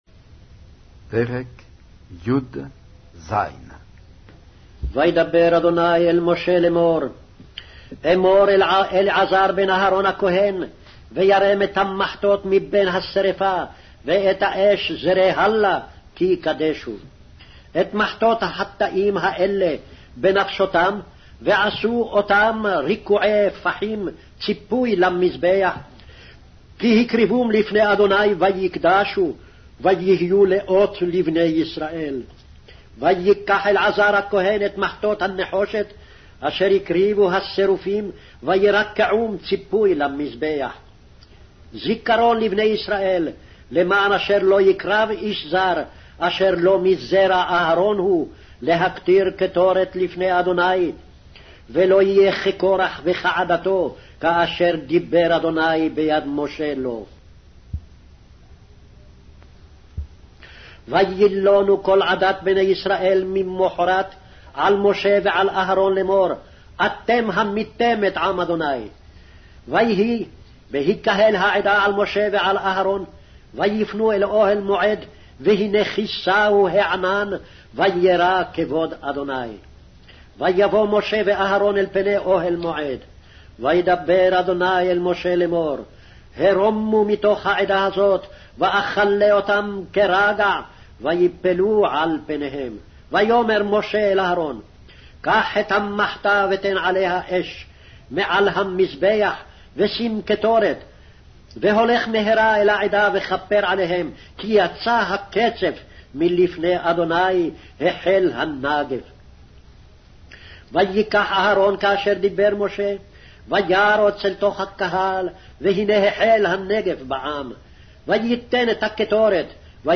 Hebrew Audio Bible - Numbers 27 in Ervta bible version